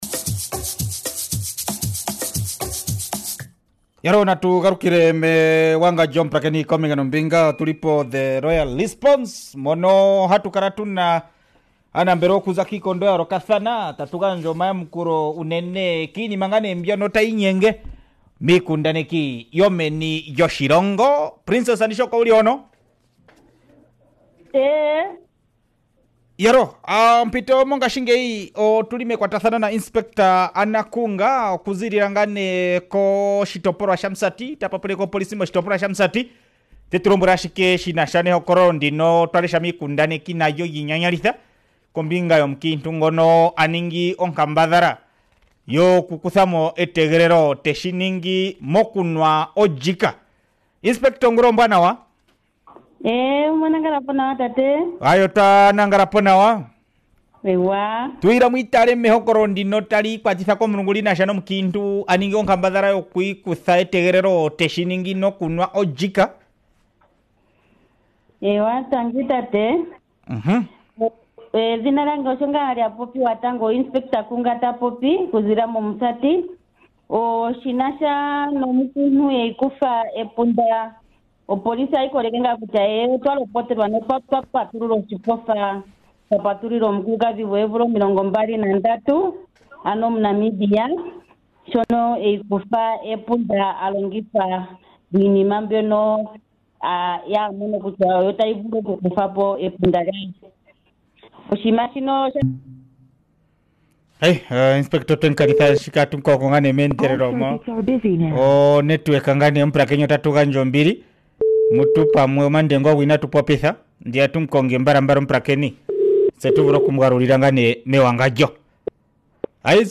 3 May Interview